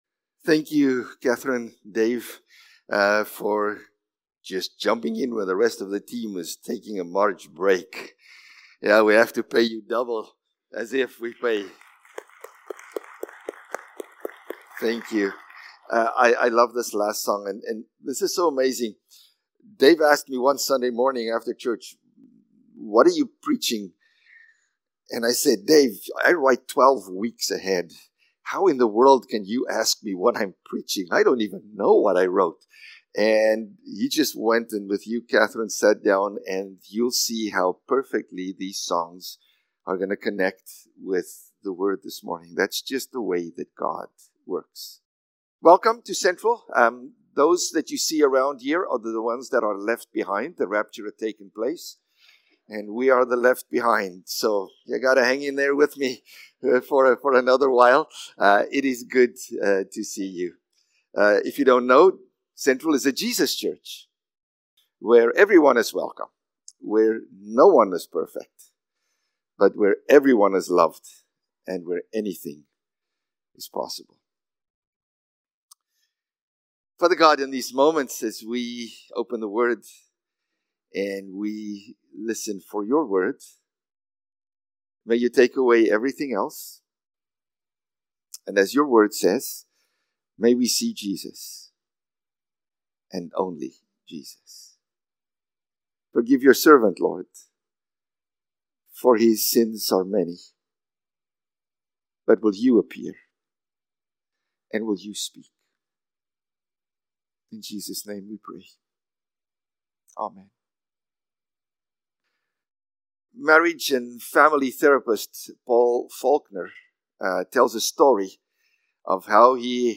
March-16-Sermon.mp3